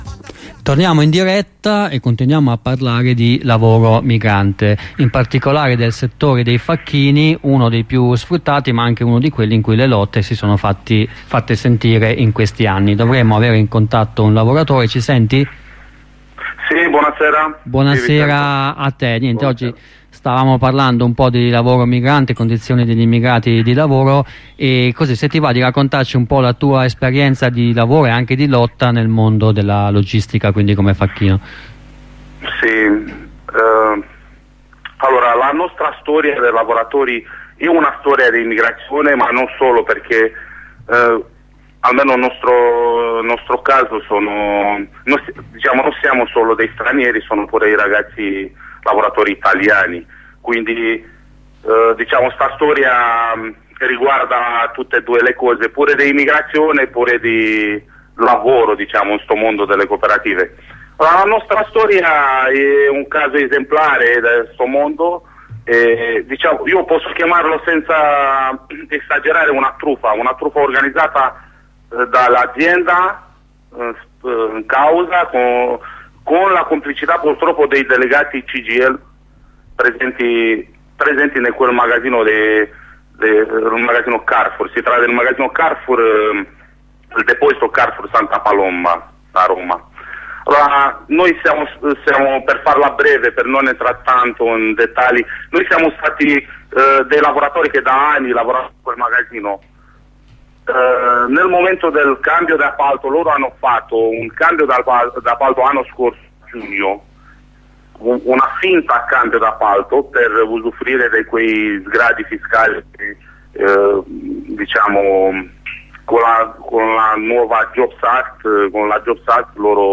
Con una compagna della Rete Campagne in lotta parliamo delle condizioni di sfruttamento e delle lotte dei braccianti nelle campagne. L'intervento inizia con una presentazione del percorso della rete e prosegue intersecandosi con i ricatti prodotti dalle leggi sull'immigrazione. A seguire l'intervento di un facchino che ci racconta le lotte in corso contro i licenziamenti e le responsabilità di sindacati e padroni.